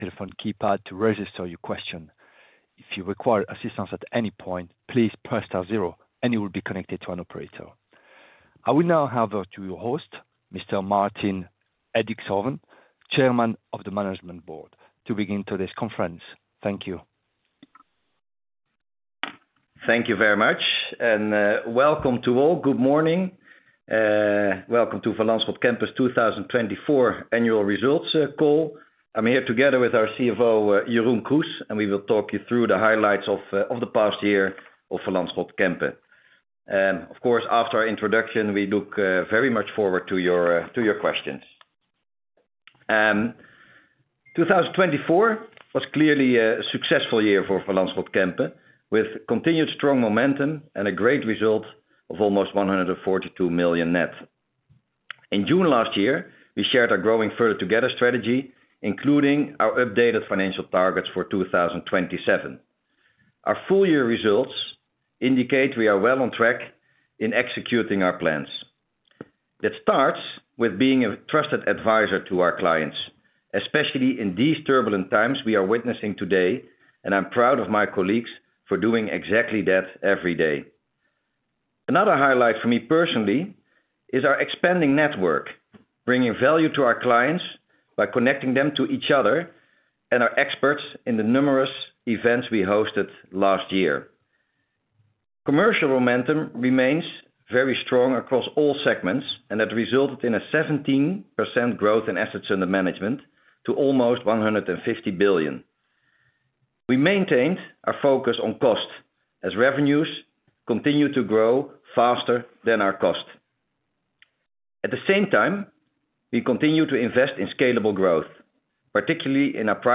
analystcallannualresults2024.mp3